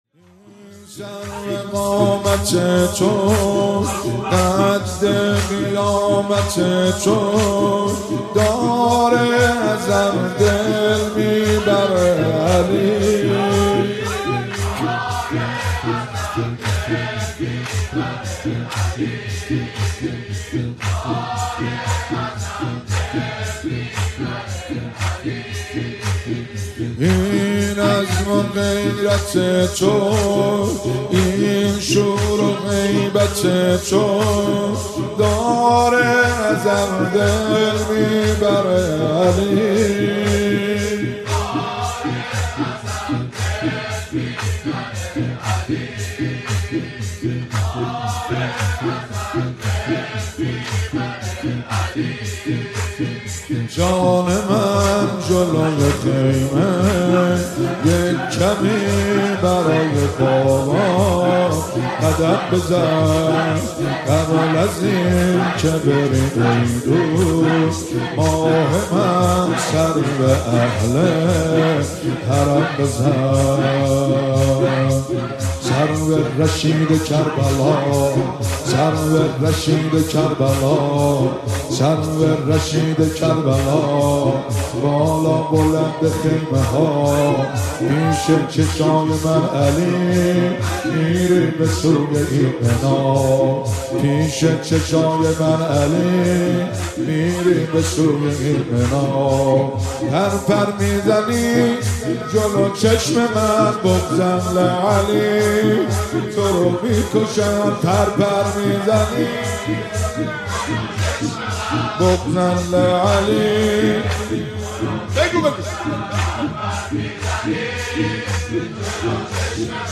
مداحی جدید حاج مهدی سلحشور مراسم هفتگی قم - هیات فاطمیون 06 آذر ماه 1398